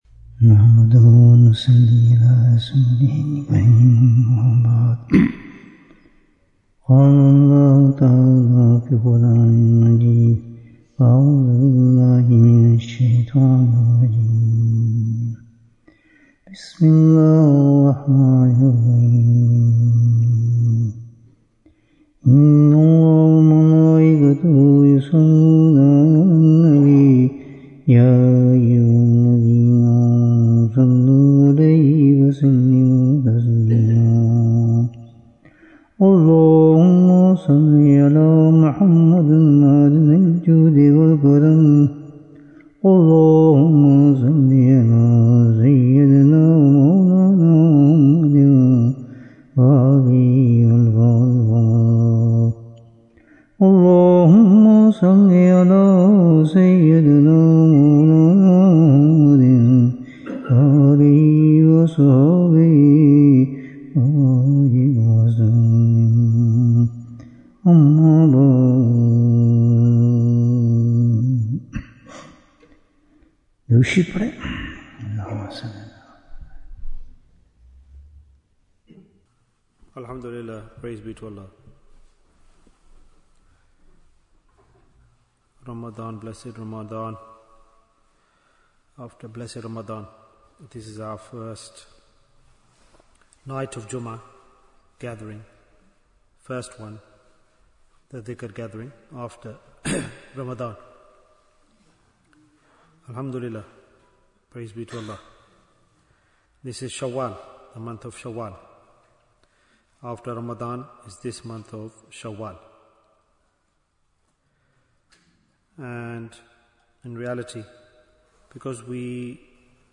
What do we do After Ramadhan? Bayan, 46 minutes26th March, 2026